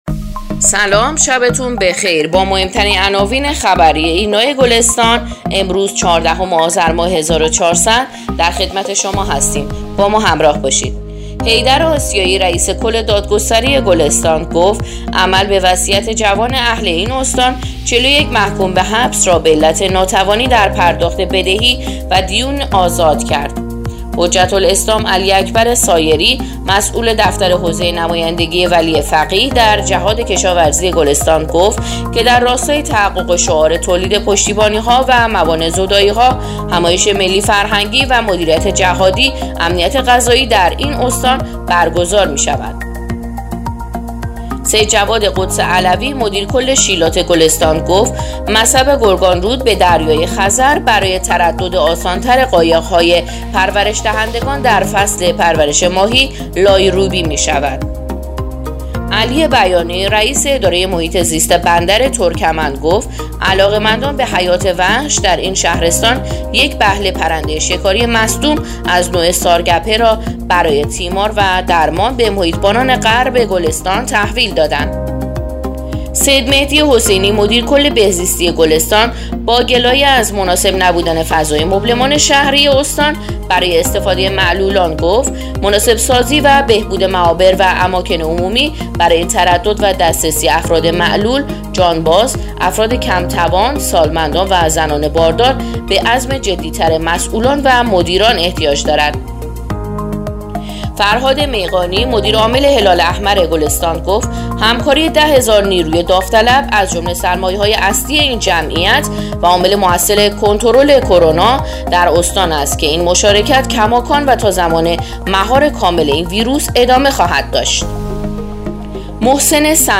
پادکست/ اخبار شبانگاه چهاردهم آذر ایرنا گلستان